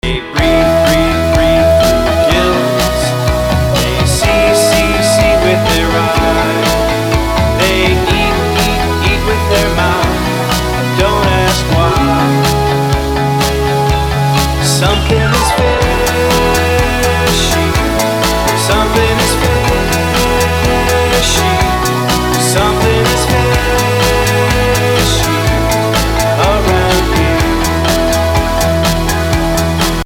Tuneful and bubbly